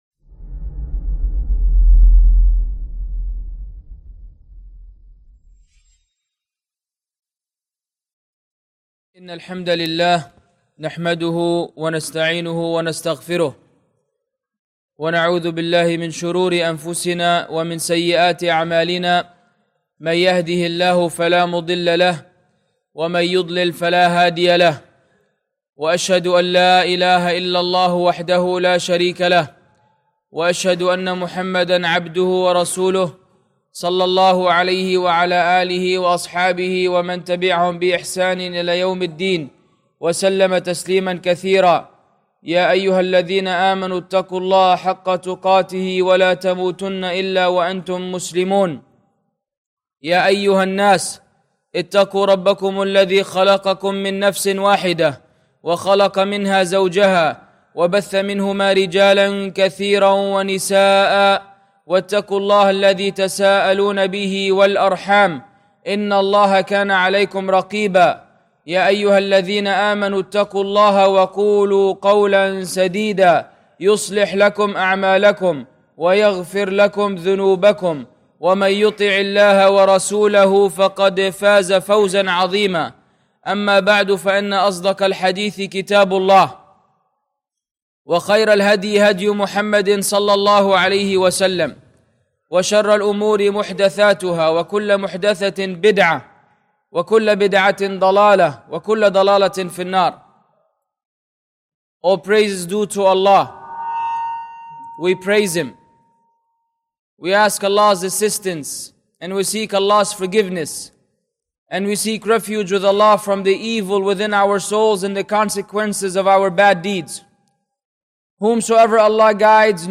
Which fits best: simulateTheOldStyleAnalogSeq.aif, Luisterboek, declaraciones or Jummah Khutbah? Jummah Khutbah